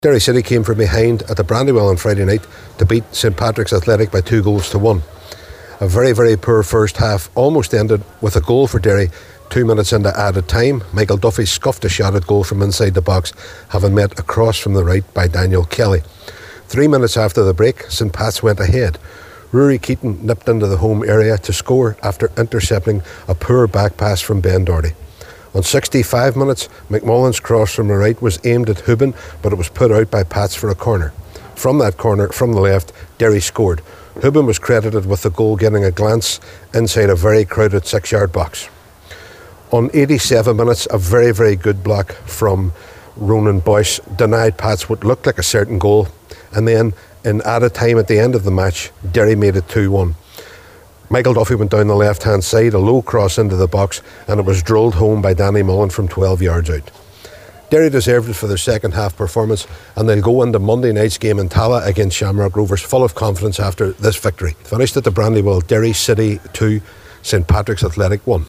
full time match report…